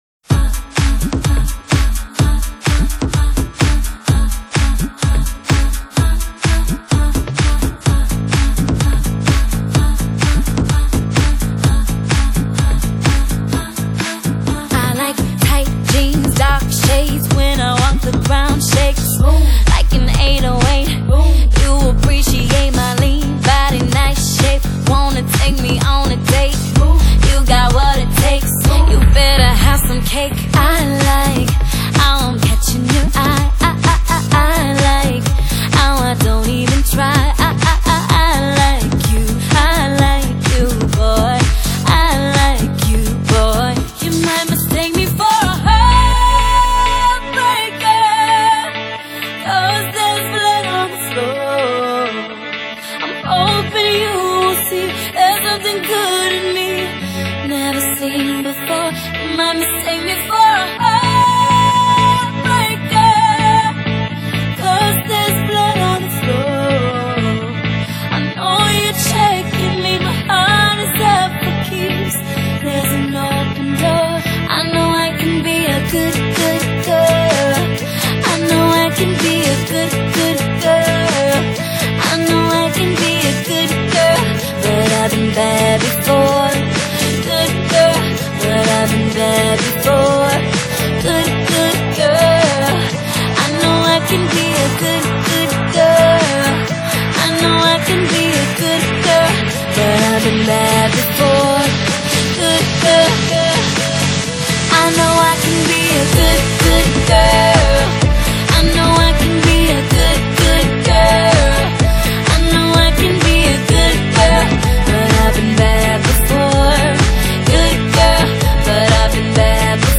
輕快流動著青春的節奏，歡快的氣息讓人情不自禁爲之舞動！